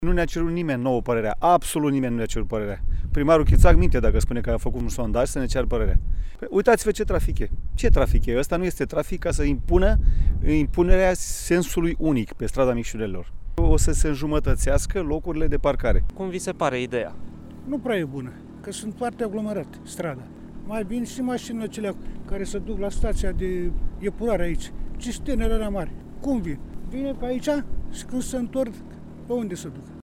Locuitorii sunt împotriva măsurii: